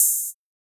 Index of /musicradar/ultimate-hihat-samples/Hits/ElectroHat B
UHH_ElectroHatB_Hit-21.wav